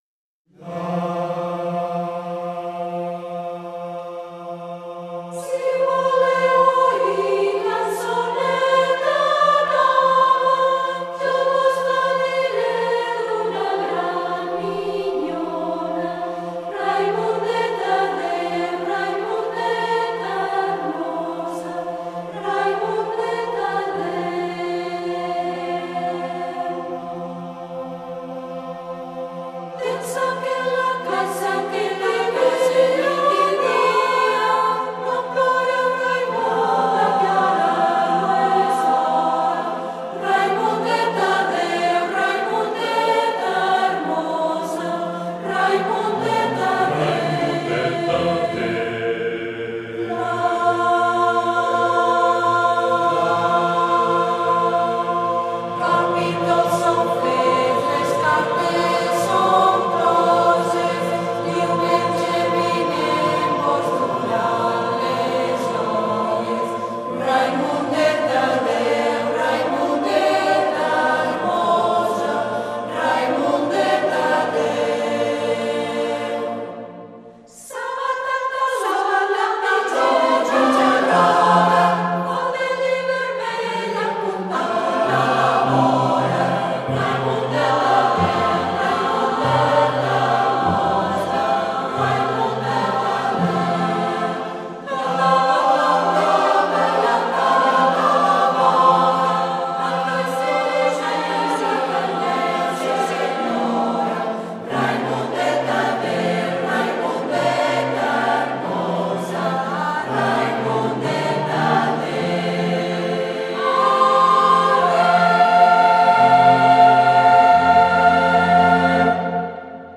Fa M
Mi 3 - Re 4